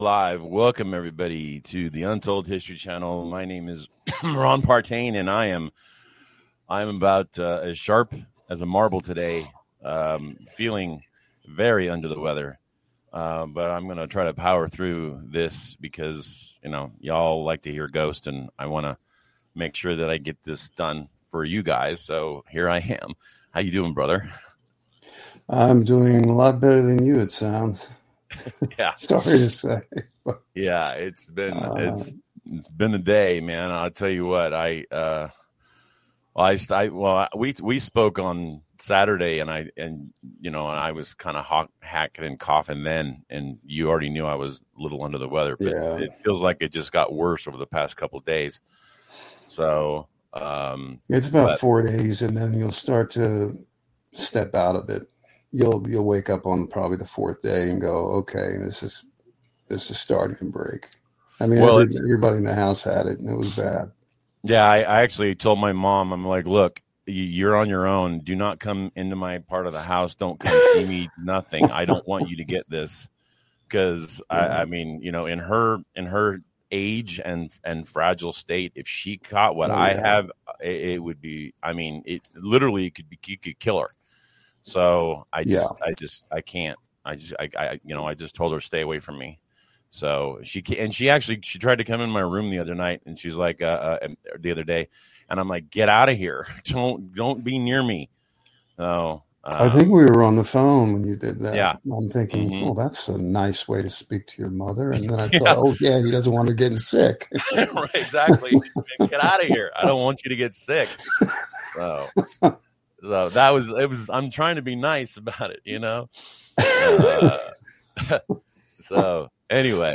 A LIVE Discussion